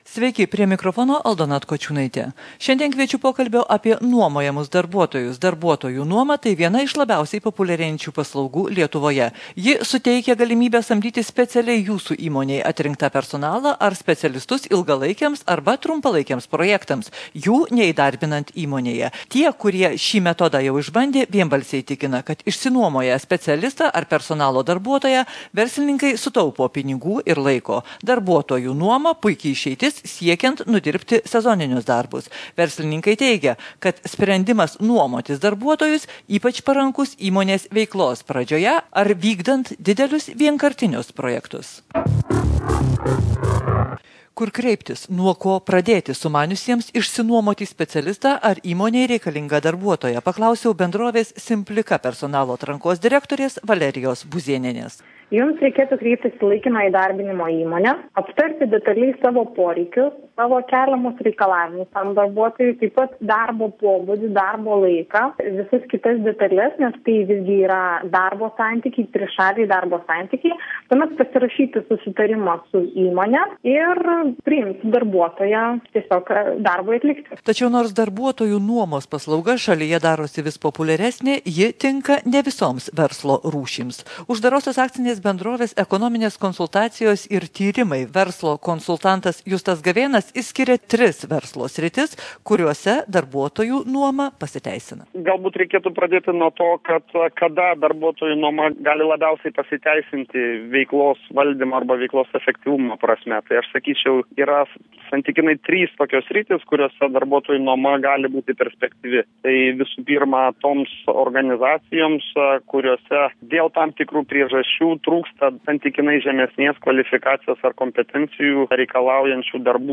2011 m. balandžio 1 dieną Žinių radijo laidoje „Verslo akademija“